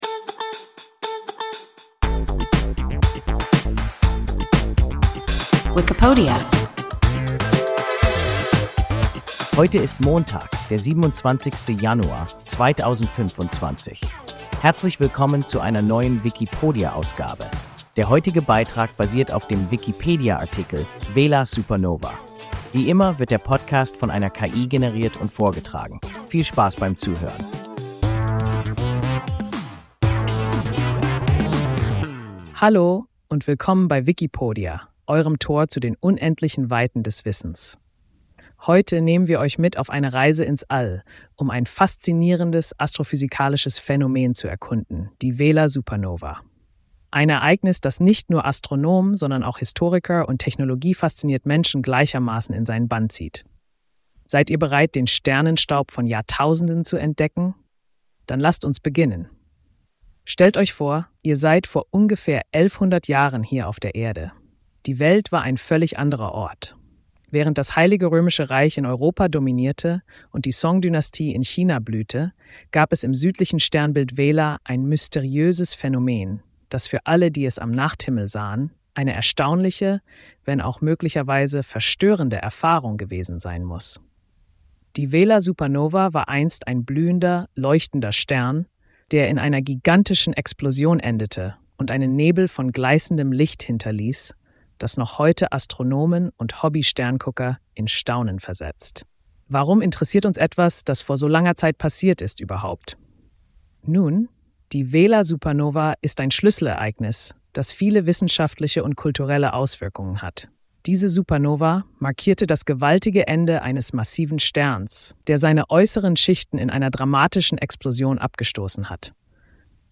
Vela-Supernova – WIKIPODIA – ein KI Podcast